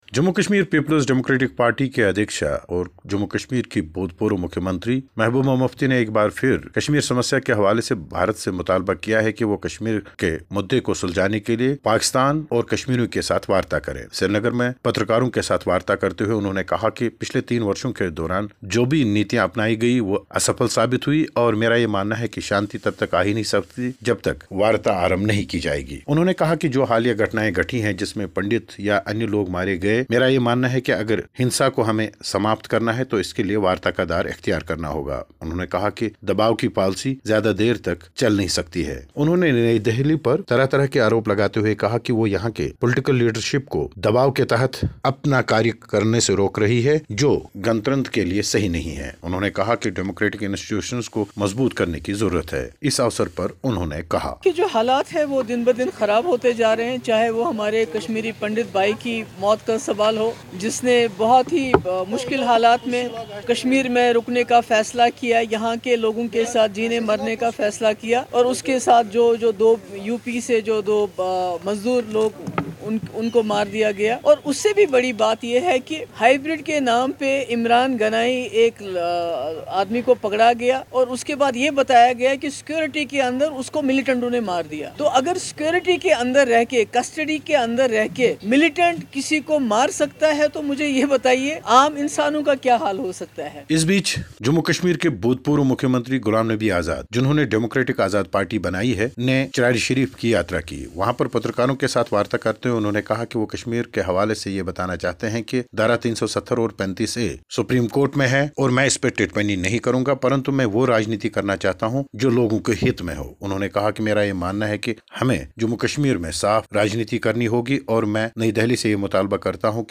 केन्द्र सरकार और प्रशासन से महबूबा के चुभते हुए सवाल...रिपोर्ट